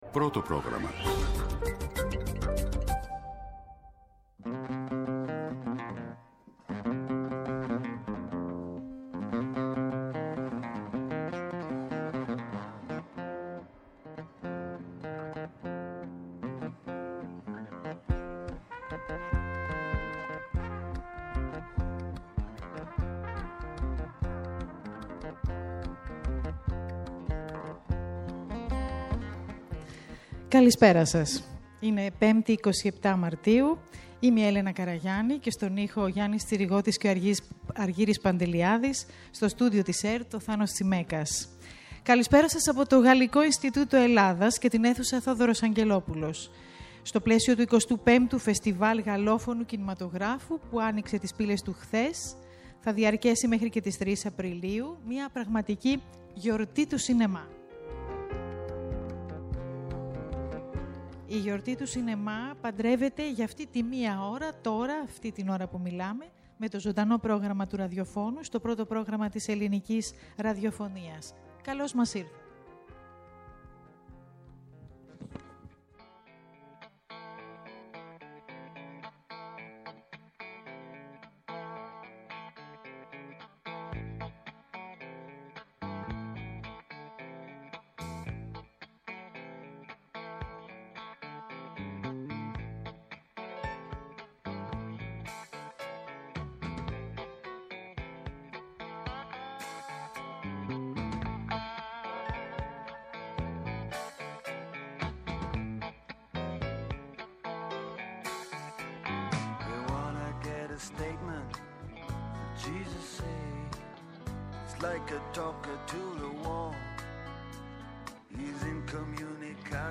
Απευθείας από το Γαλλικό Ινστιτούτο για το 25ο Φεστινάλ Κινηματογράφου.